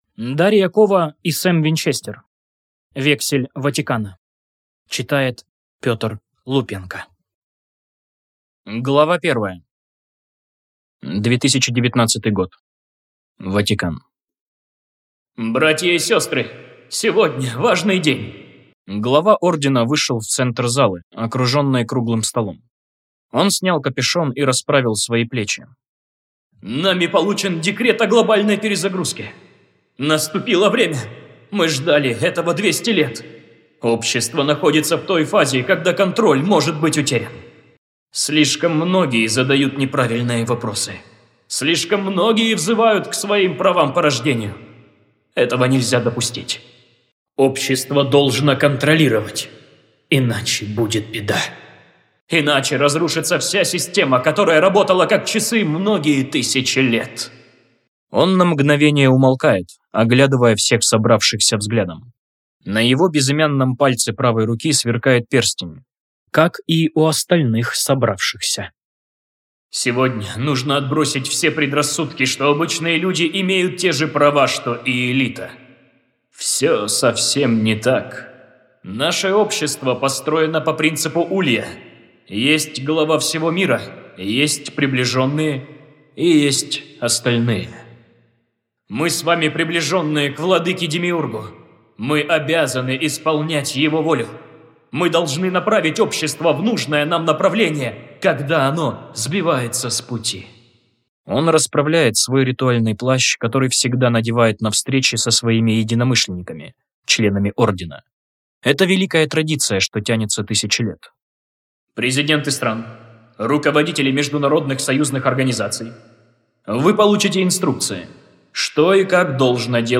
Аудиокнига Вексель Ватикана | Библиотека аудиокниг